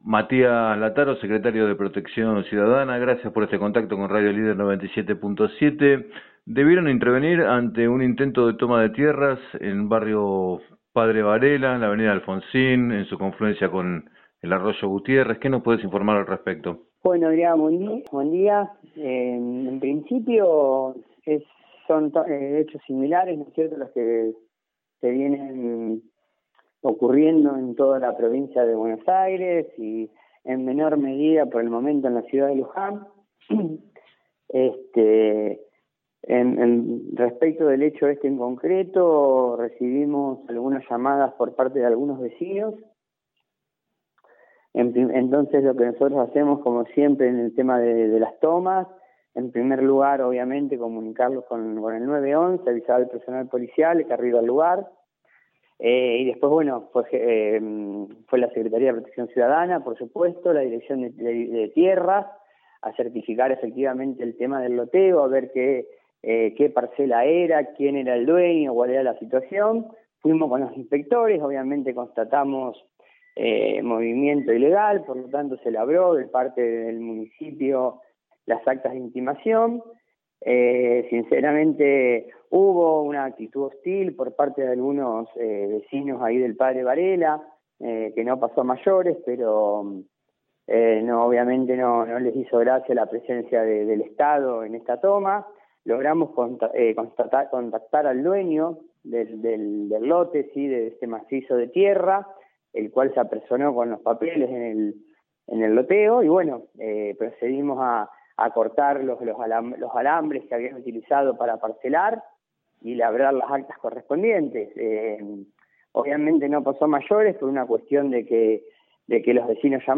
En diálogo con Radio Líder 97.7, Lattaro se refirió a una ocupación que fue abortada este lunes en barrio Padre Varela como parte de “hechos similares a los que vienen ocurriendo en toda la Provincia”.